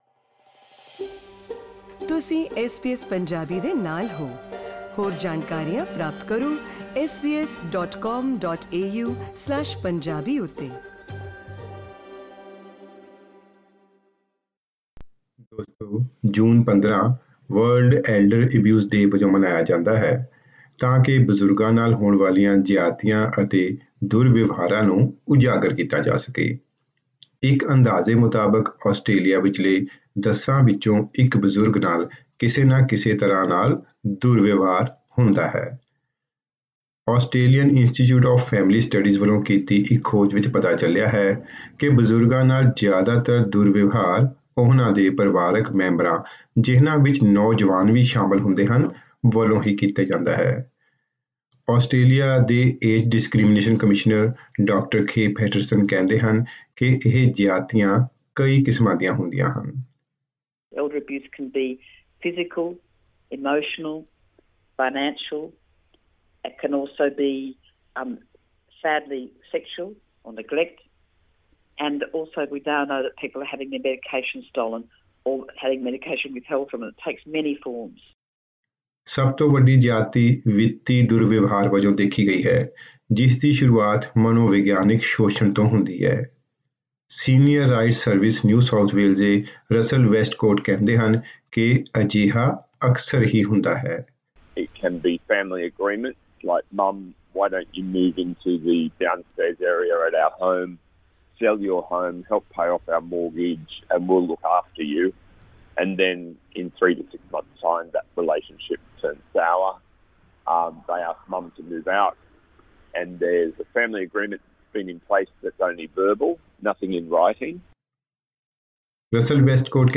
radio_feature_-_elder_abuse_day_15_june_-_mp3_0.mp3